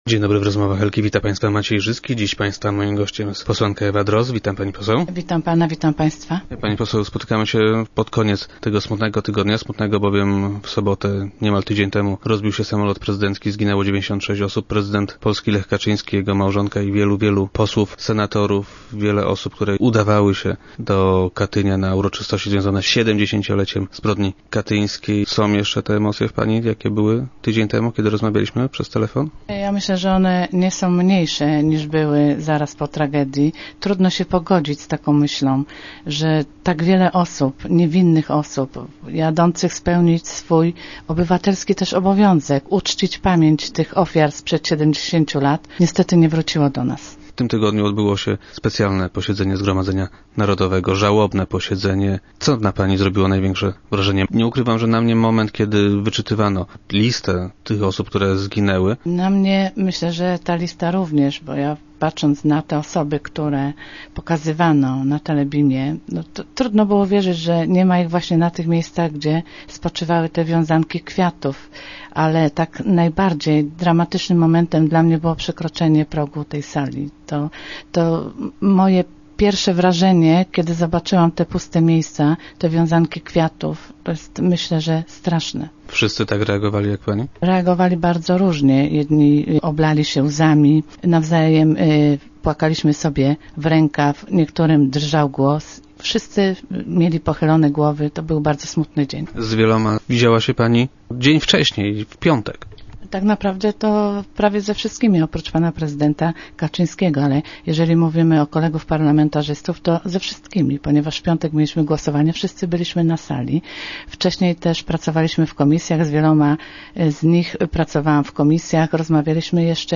Posłanka Ewa Drozd, która była dziś gościem piątkowych Rozmów Elki, ma nadzieję, że tak.